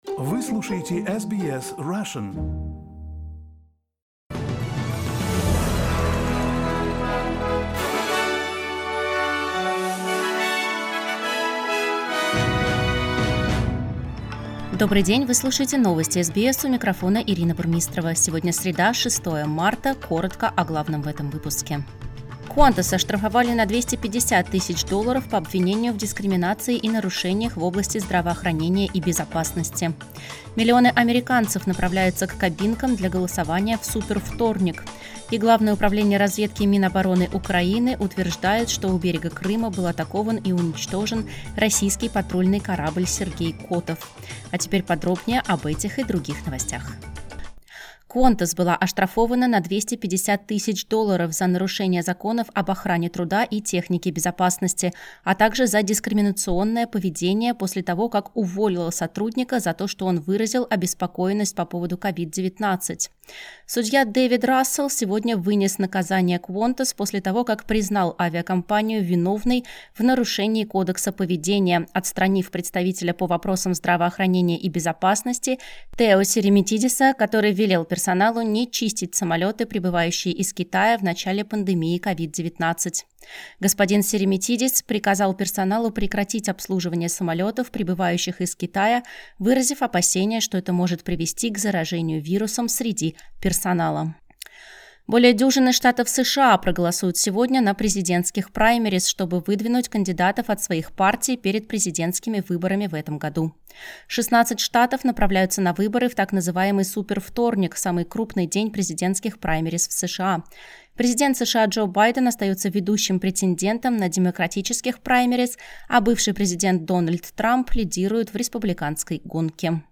Listen to the top Australian and world news by SBS Russian.